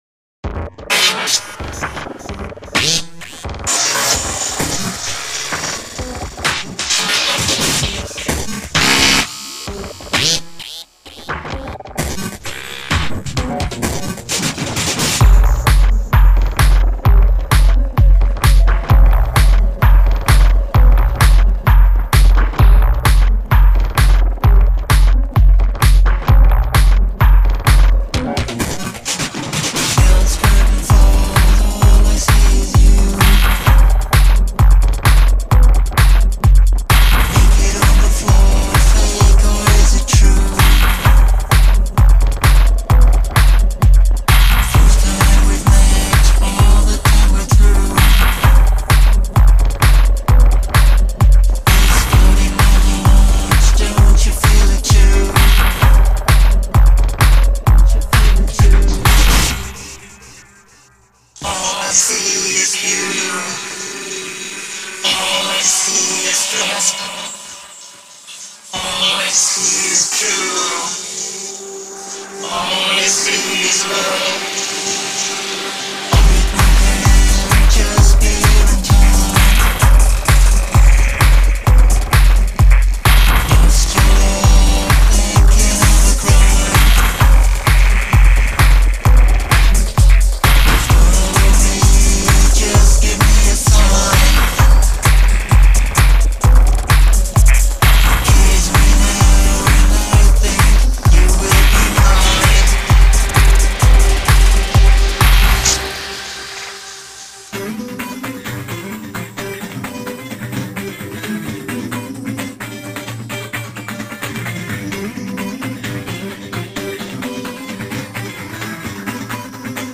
schräger.ffmknüppel.4/4.frickel.flächentechno
sehr trashig das ganze, aber durchaus hörenswert.